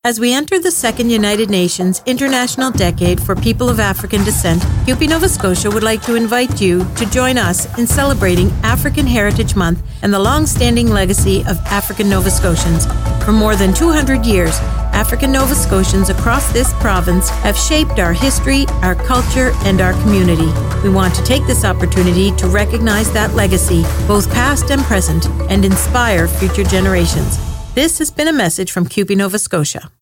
Radio Transcript: